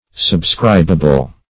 subscribable - definition of subscribable - synonyms, pronunciation, spelling from Free Dictionary
Subscribable \Sub*scrib"a*ble\, a.